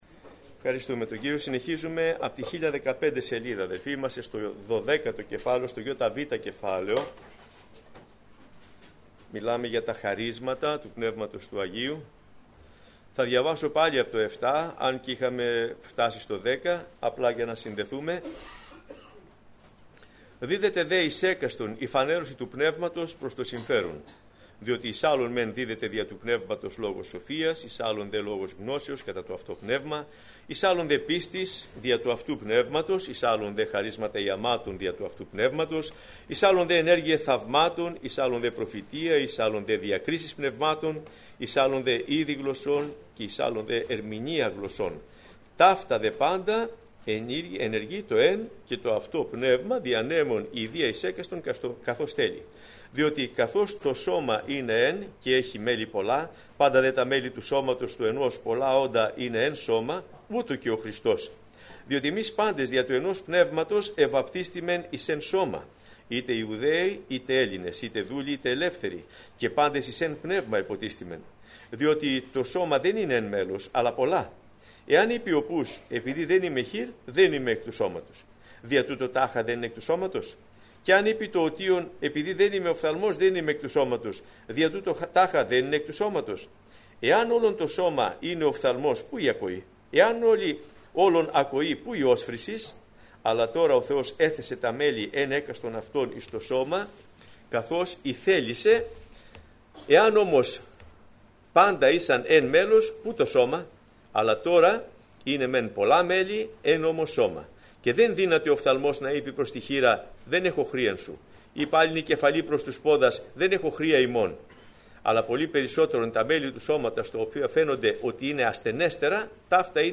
Σειρά: Μαθήματα